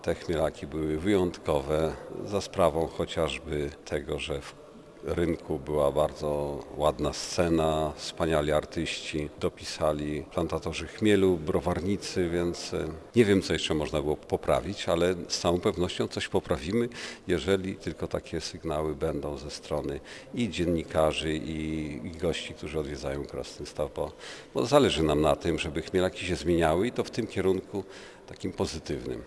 Burmistrz Krasnegostawu Andrzej Jakubiec podkreśla, że obecna ocena "Chmielaków" to efekt nie tylko ponad 40-letniego doświadczenia, ale także sugestii gości, które co roku starają się wdrożyć organizatorzy: